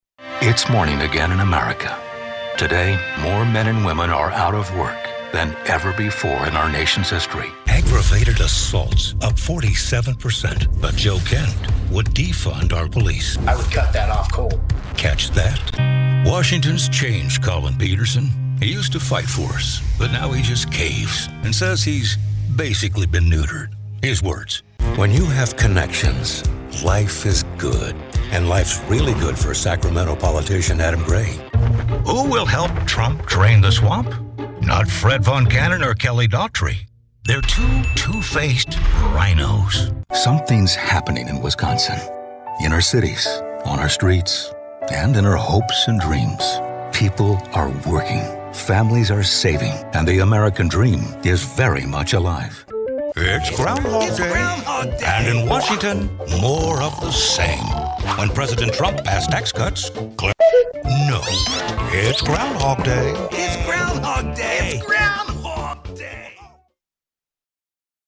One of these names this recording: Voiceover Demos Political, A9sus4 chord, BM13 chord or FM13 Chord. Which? Voiceover Demos Political